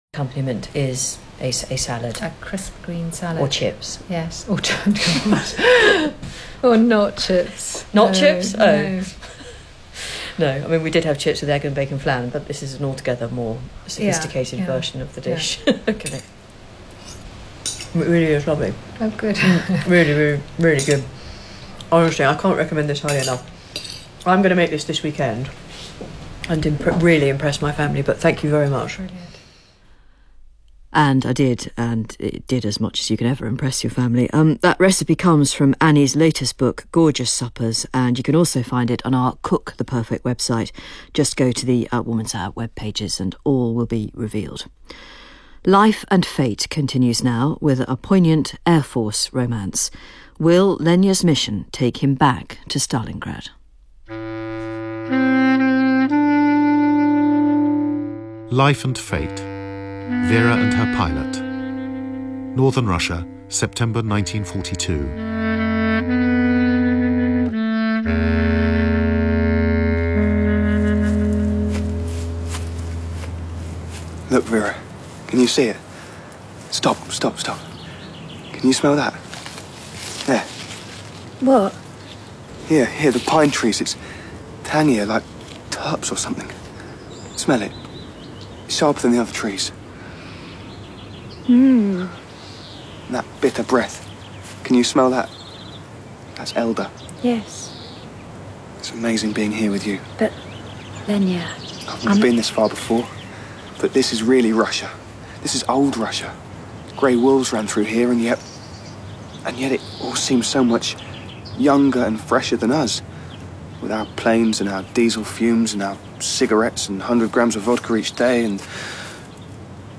This epic saga takes over every drama strand on Radio 4 this week.